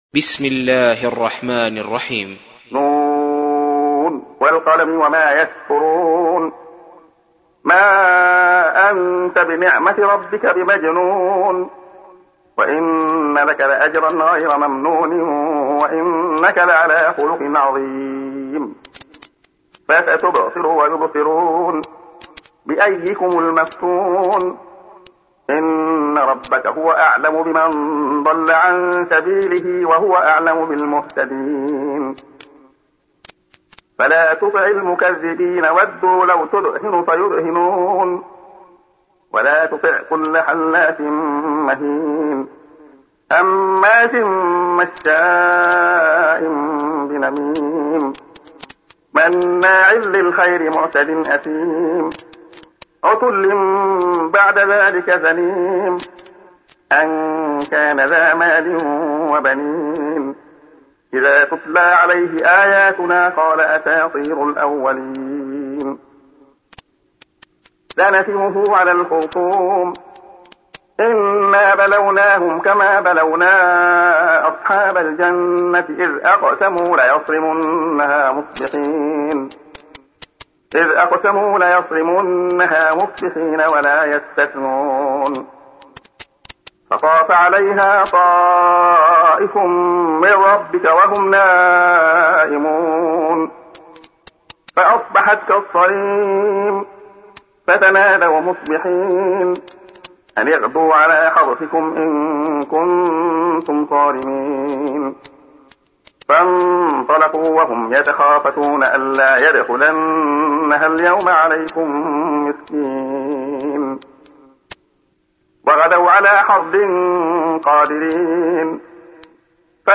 سُورَةُ القَلَمِ بصوت الشيخ عبدالله الخياط